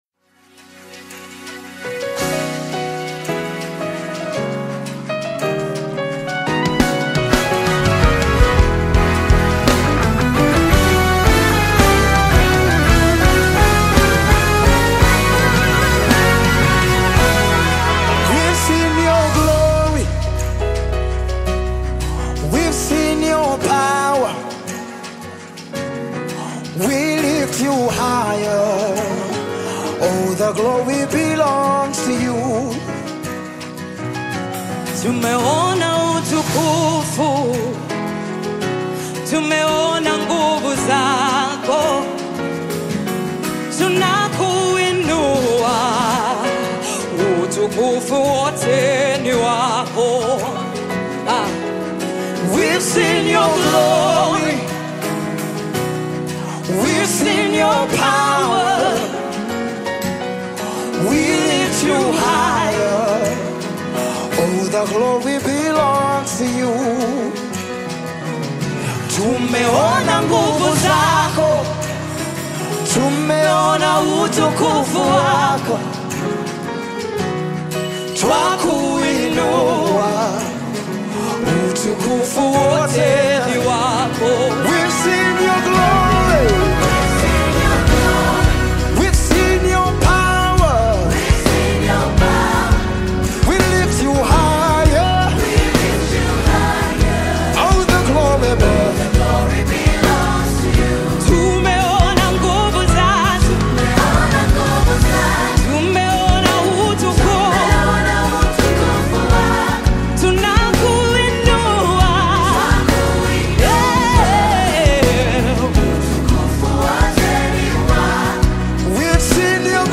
Gospel music track
Tanzanian gospel artists